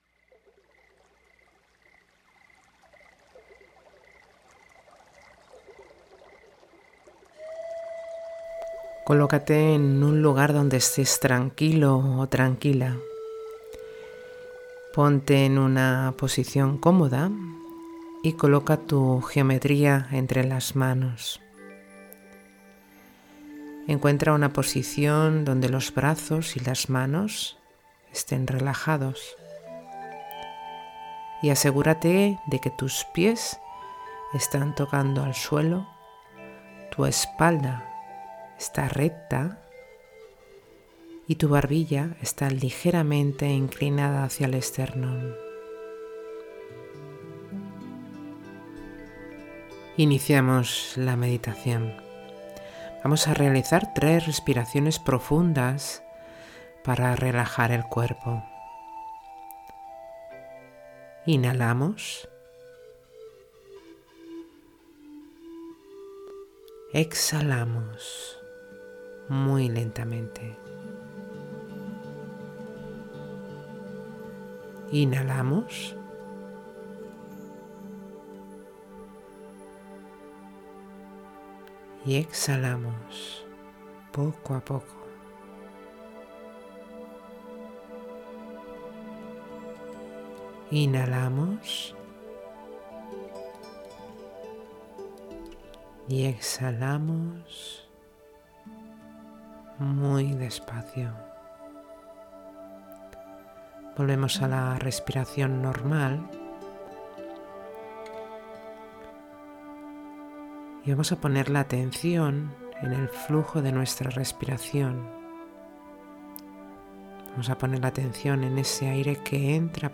Meditación Básica Dodecaedro Truncado